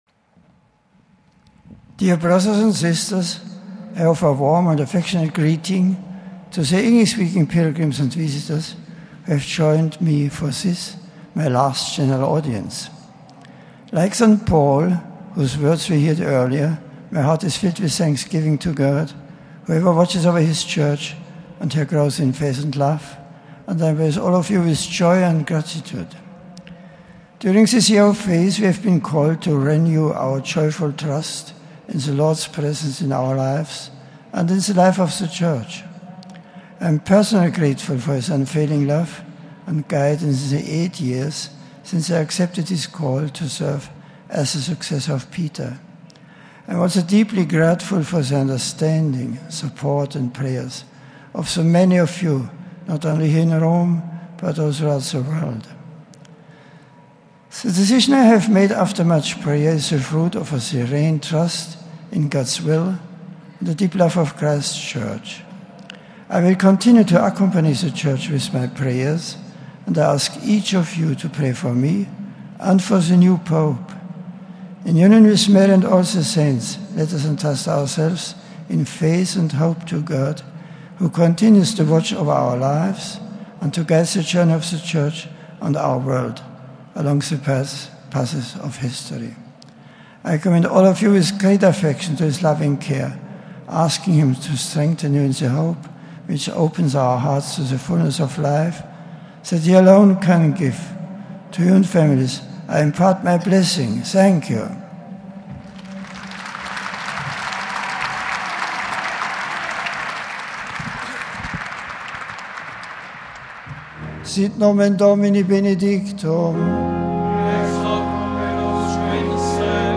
“To love the church means also to have the courage to take difficult, painful decisions, always keeping the good of the church in mind, not oneself,'' Pope Benedict said to a thunderous applause.
Pope Benedict XVI, also delivered summaries of his catechesis in several languages, including in English.